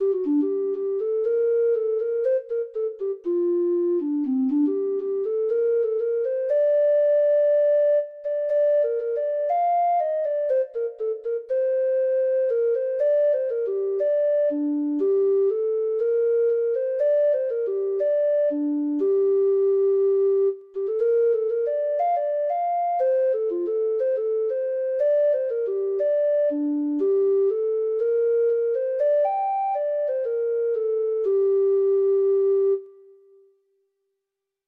Traditional Trad. The Cruiskeen Lawn (Irish Folk Song) (Ireland) Treble Clef Instrument version
Traditional Music of unknown author.
Irish